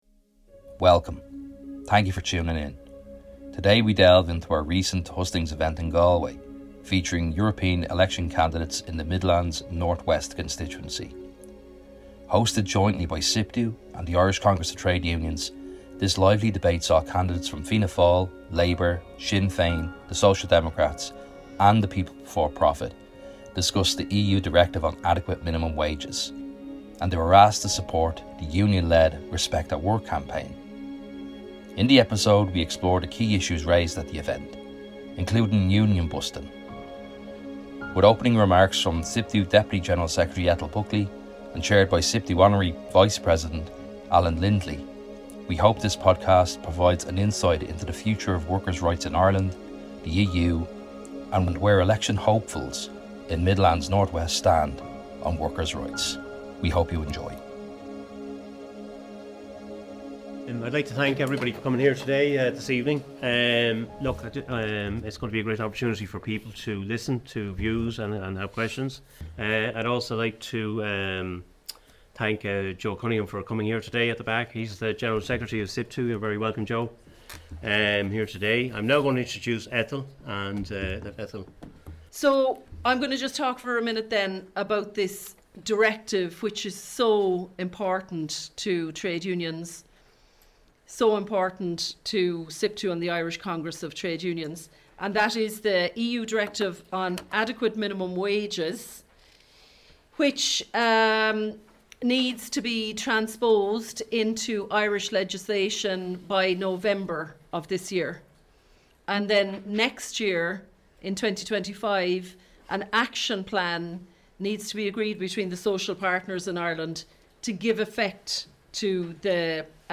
Special Podcast: Listen back to a lively debate on workers’ rights between European Election candidates in Midlands North West constituency.